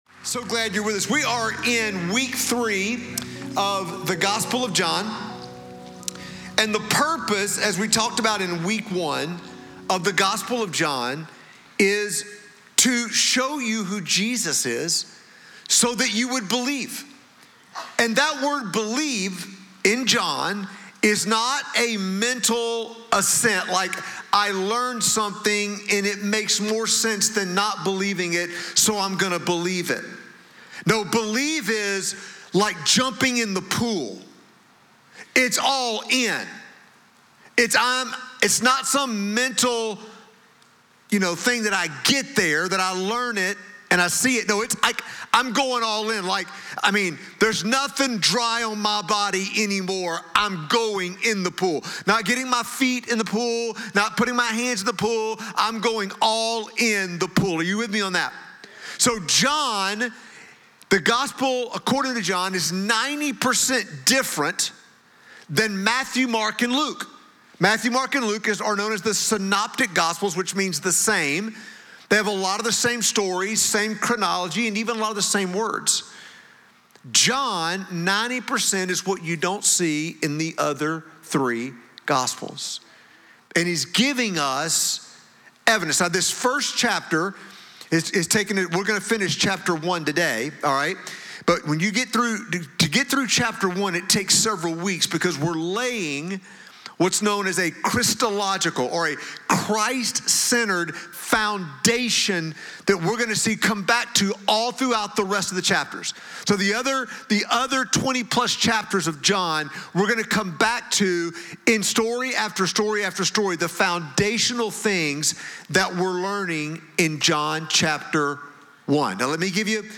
Follow this podcast to stay up-to-date on recent messages from our Sunday morning environments.
A Collection of Messages from The Creek Church